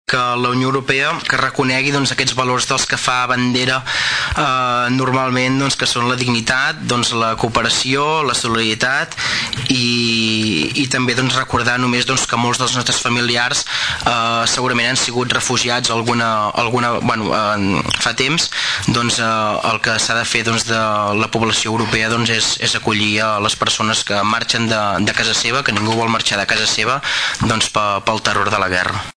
El regidor d’ERC i Gent de Tordera, Jordi Romaguera creu que la decisió adoptada per la Unió Europea propicia que hi hagi més màfies i tràfic il·legal de persones.